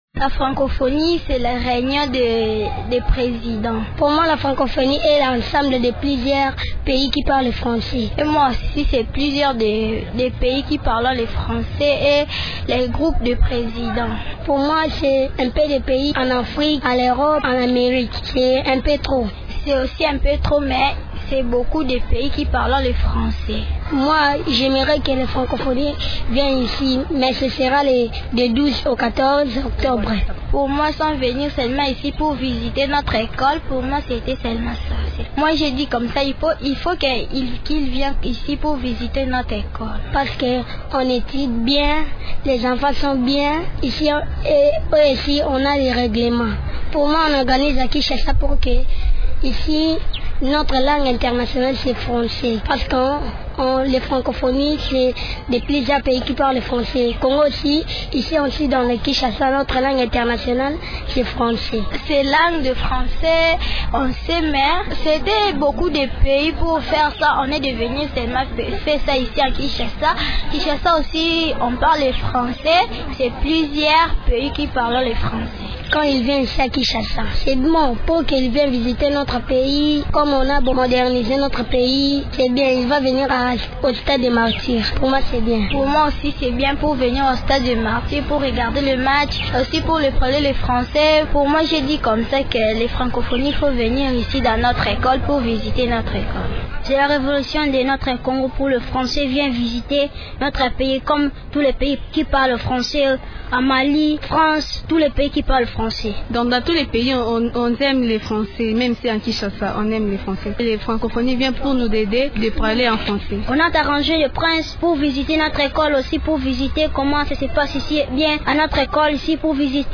Actualité oblige, les enfants parlent de la Francophonie.